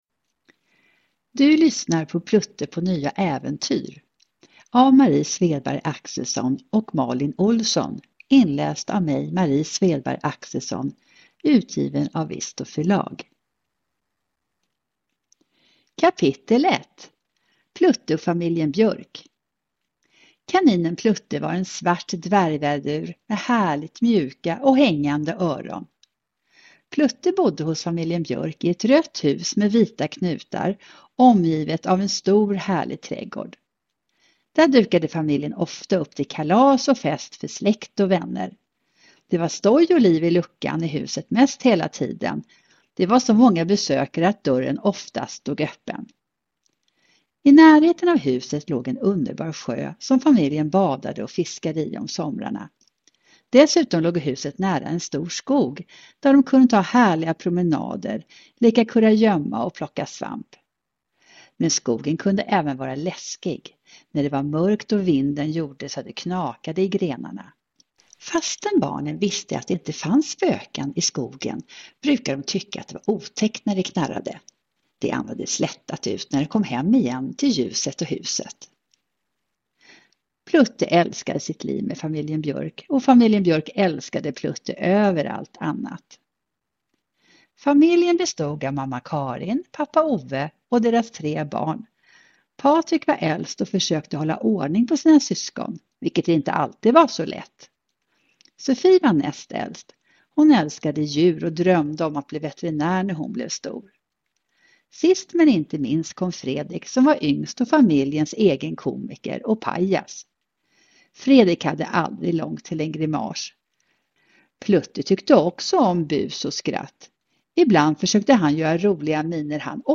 Plutte på nya äventyr (ljudbok